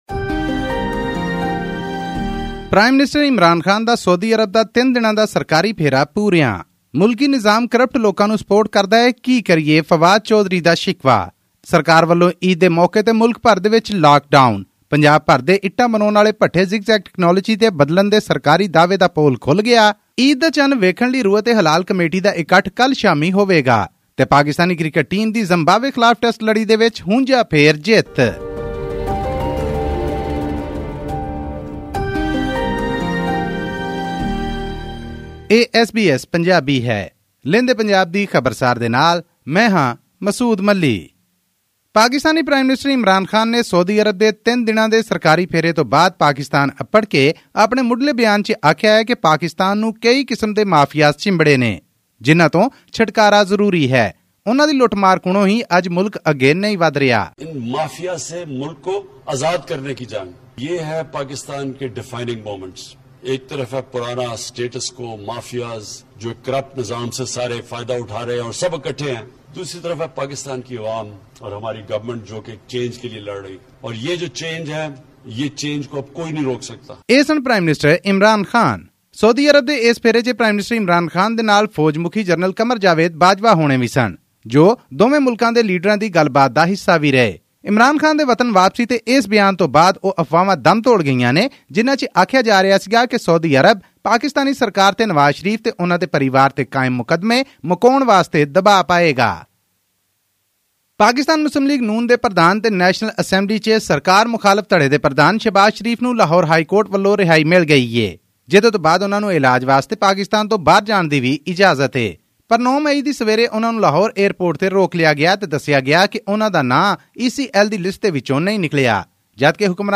Pakistan has imposed a strict nine-day shutdown affecting travel and tourist hotspots to contain the rising number of COVID-19 infections ahead of Eid al-Fitr. All this and more in our weekly news bulletin from Pakistan.